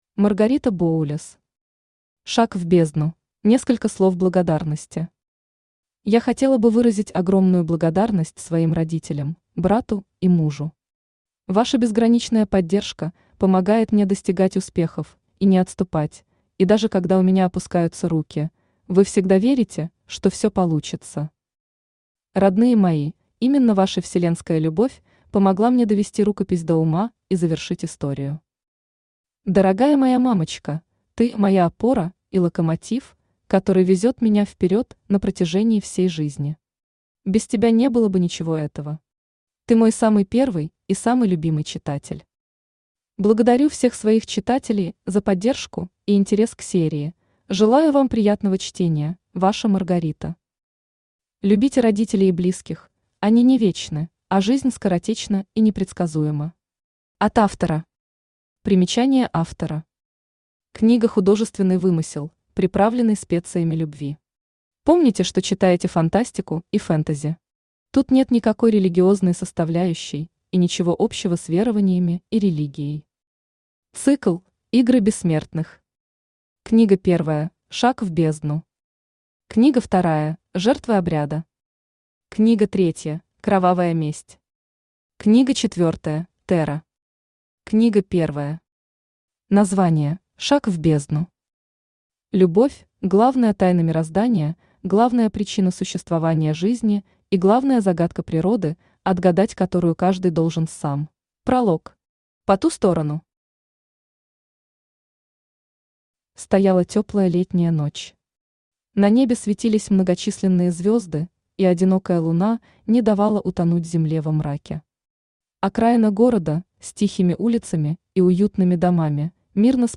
Аудиокнига Шаг в Бездну | Библиотека аудиокниг
Aудиокнига Шаг в Бездну Автор Маргарита Боулес Читает аудиокнигу Авточтец ЛитРес.